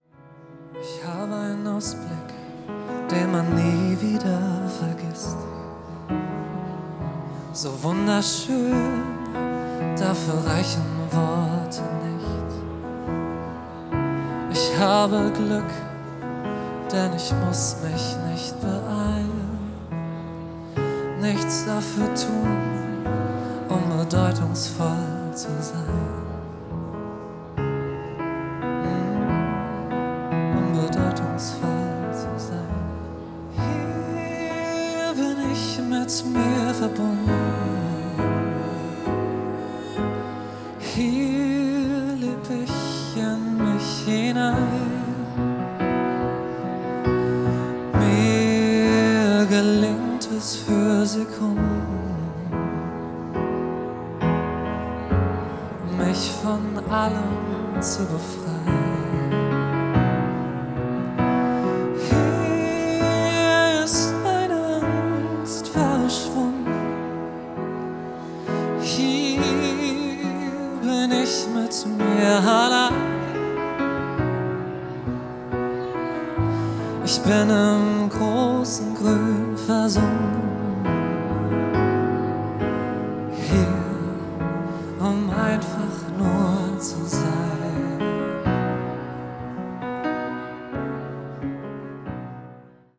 live von der celebrations Hochzeitsmesse Frankfurt